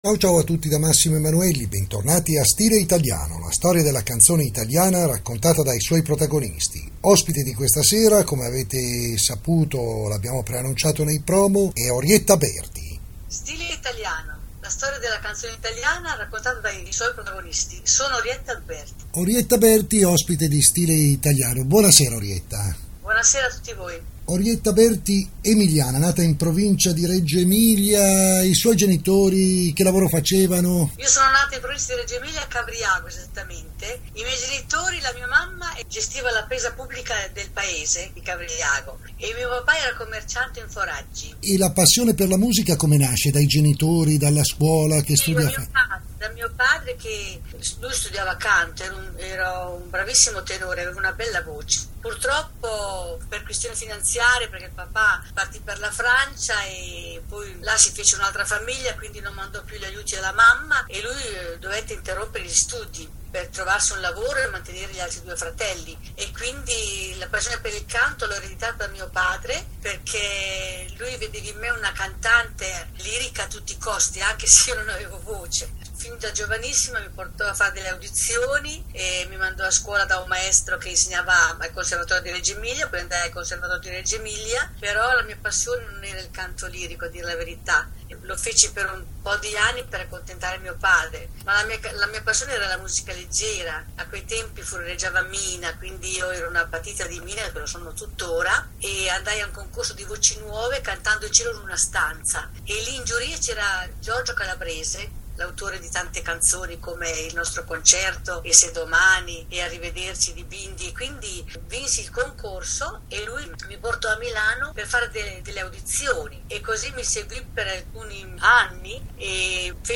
Ascolta la puntata della trasmissione radiofonica STILE ITALIANO con ospite Orietta Berti.
orietta-berti-solo-parlato.mp3